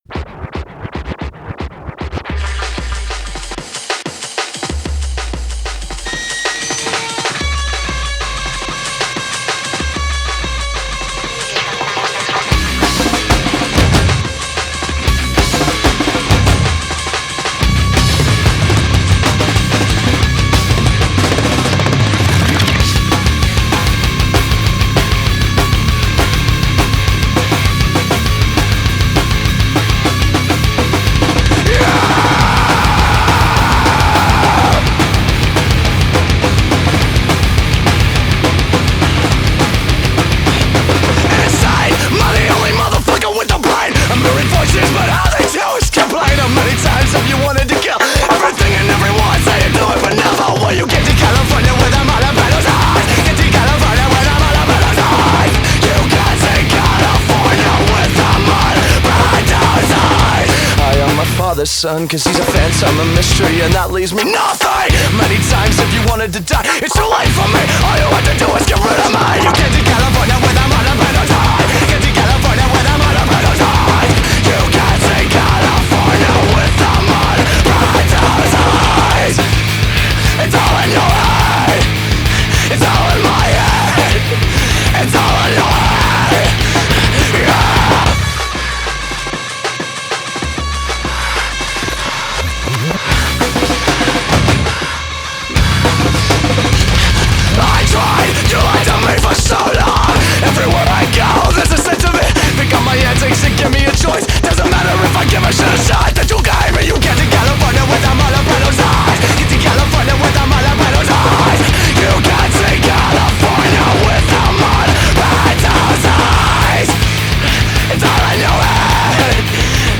2025-01-03 17:30:08 Gênero: Rock Views